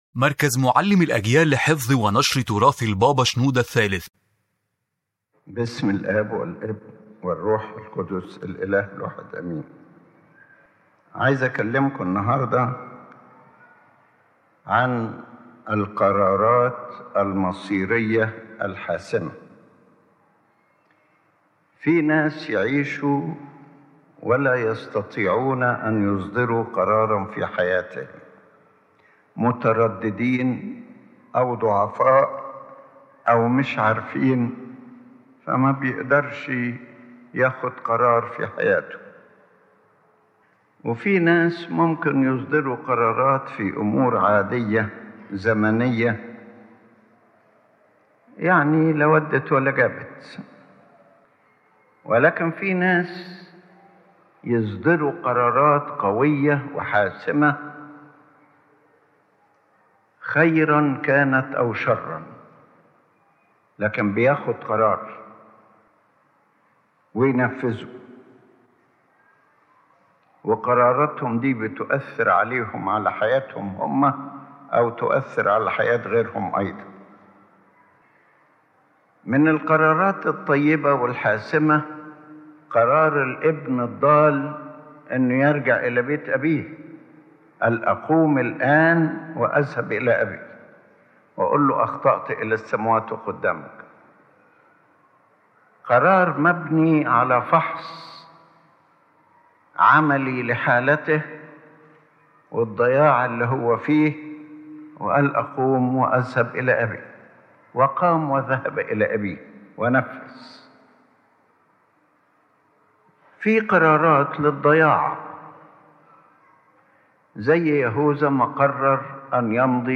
This lecture discusses the importance of crucial life decisions and how decisive choices, when built on faith and spiritual discernment, can change a person’s destiny or even the course of history—either for good or for destruction.